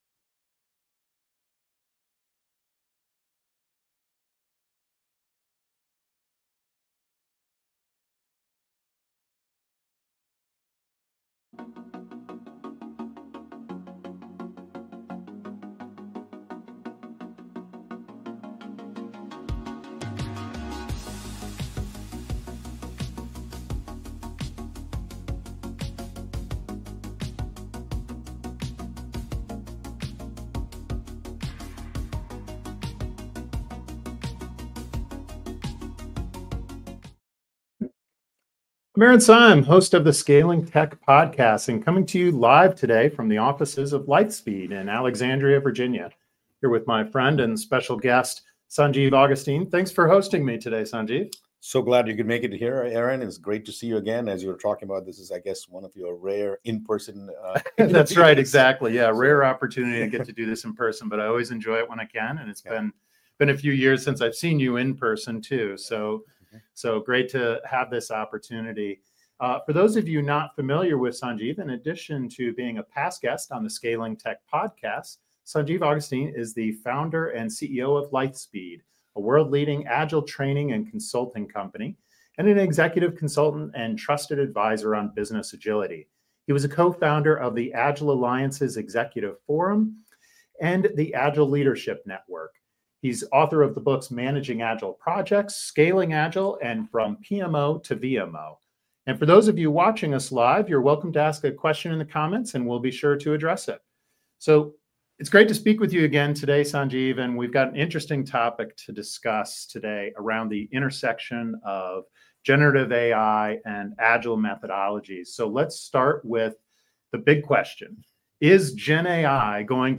along with his guests, brings you the insights you need to handle all the human and technical aspects of managing a growing software team.